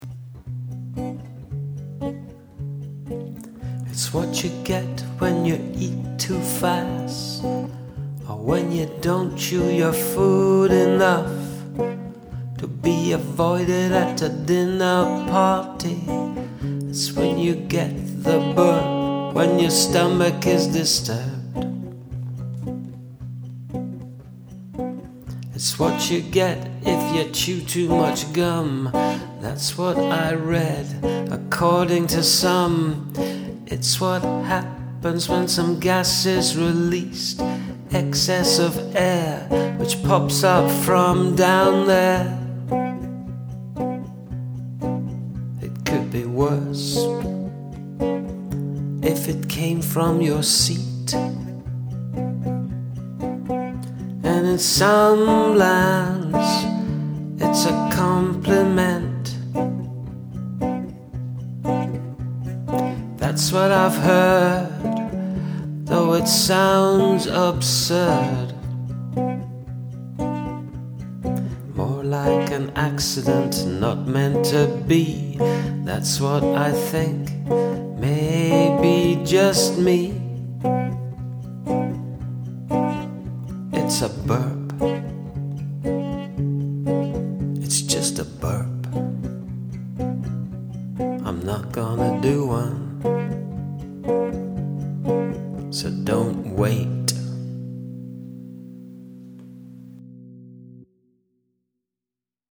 Love your sense of humour: lyrics, delivery and playing guitar. You have a very unique style- very entertaining indeed.